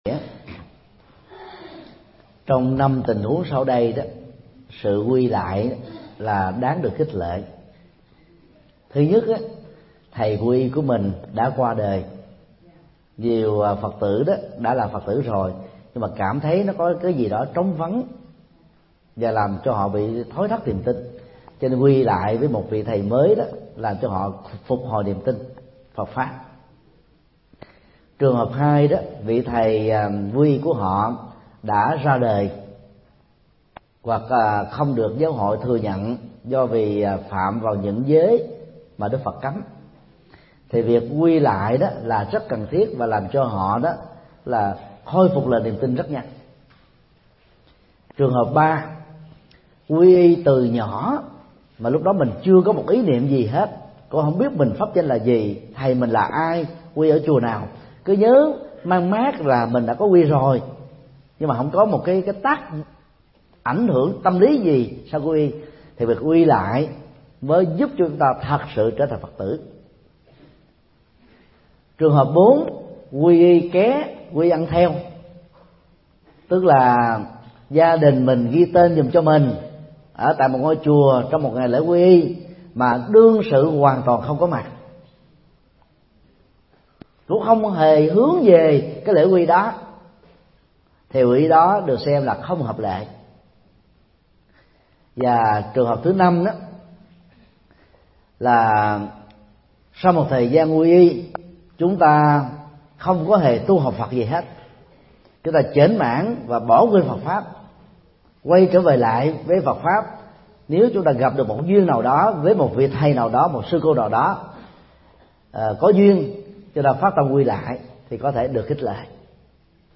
Vấn đáp: Quy y lại – Mp3 Thầy Thích Nhật Từ Thuyết Giảng